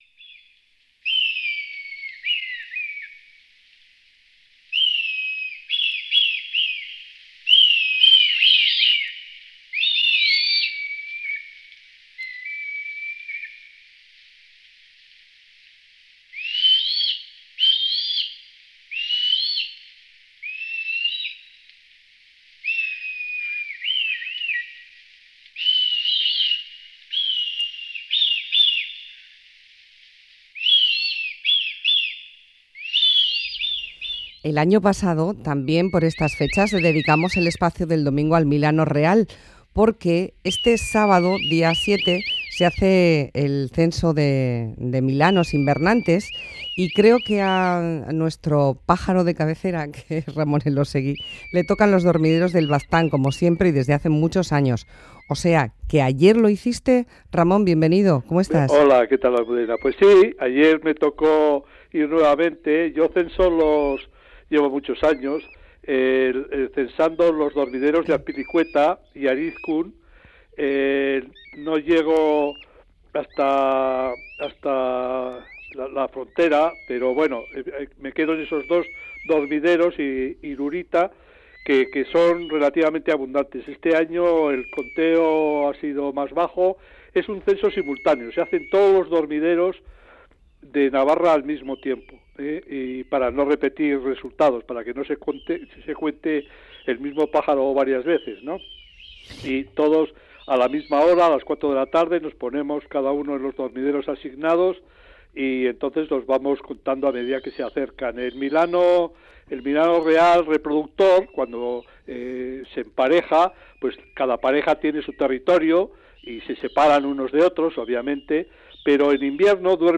Milano real